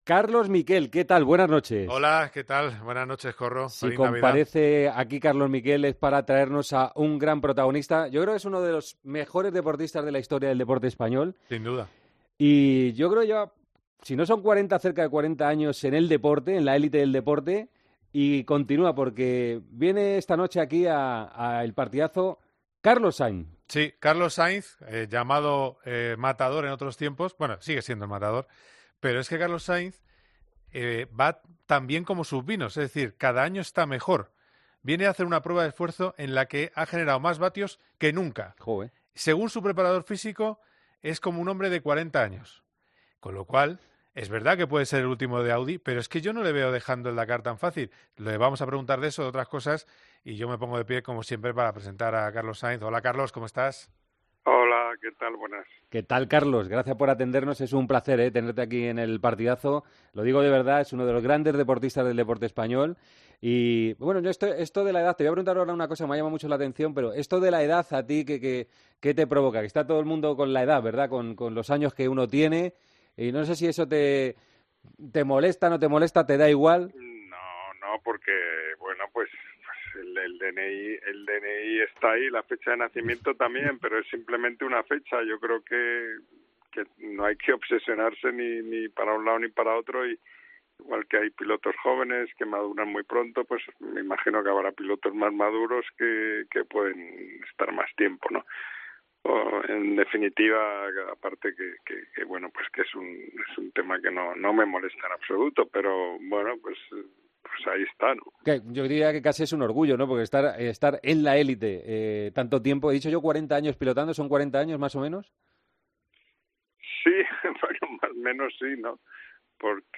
AUDIO: El piloto de resistencia participará por decimoséptima vez en el Rally Dakar y pasó este viernes por los micrófonos de El Partidazo de COPE.